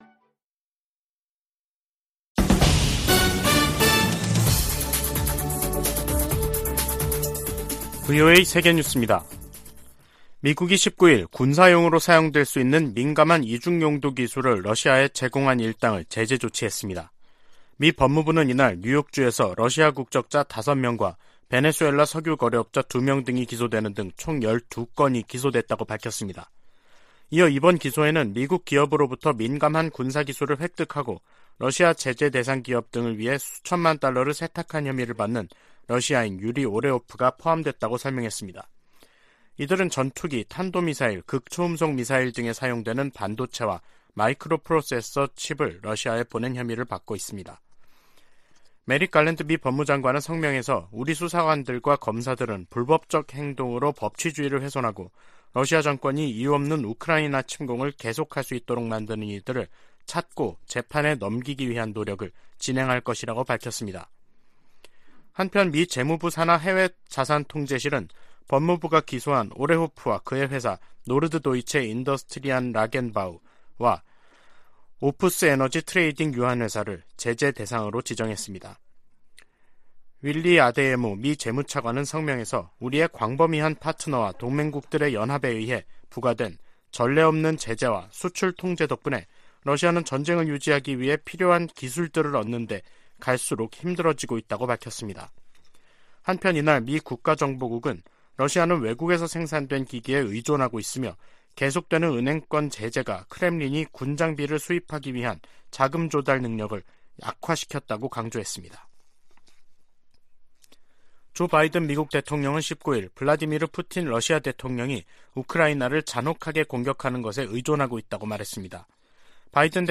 VOA 한국어 간판 뉴스 프로그램 '뉴스 투데이', 2022년 10월 20일 2부 방송입니다. 미 국무부는 연이은 북한 포 사격에 심각한 우려를 나타내며 한국과 일본에 악영향을 줄 수 있다고 지적했습니다. 미 공군 전략폭격기 B-1B가 괌에 전개됐다고 태평양공군사령부가 확인했습니다. 한국의 다연장 로켓 구매 계약을 체결한 폴란드는 러시아의 침공을 저지하기 위해 이 로켓이 필요하다고 설명했습니다.